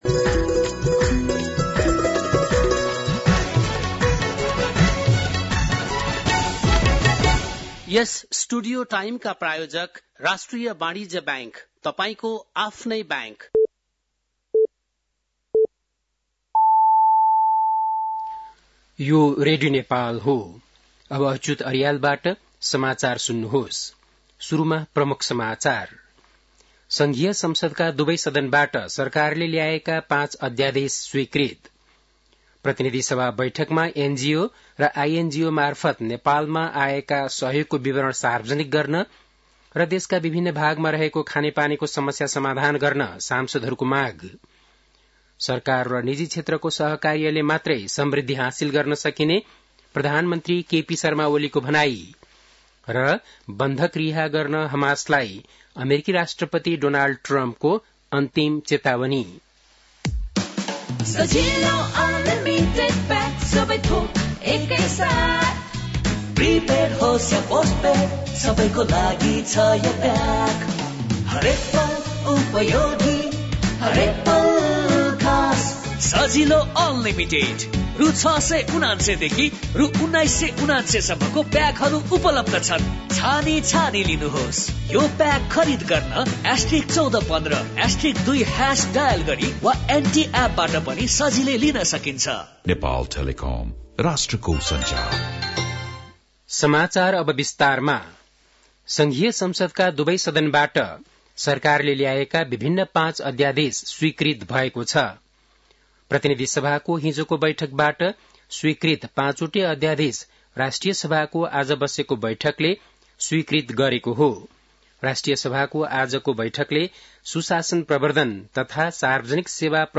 बेलुकी ७ बजेको नेपाली समाचार : २३ फागुन , २०८१